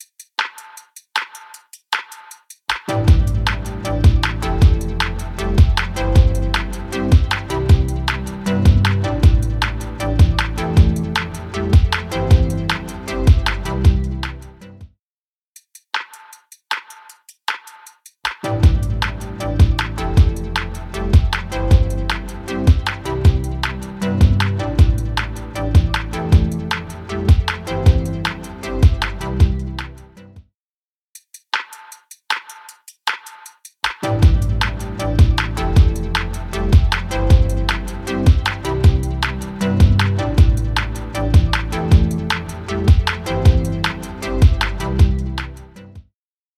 Invigorate | Electronic Beat | Preset: Fuller and Brighter
Invigorate-Electronic-Beat-Fuller-and-Brighter-CB.mp3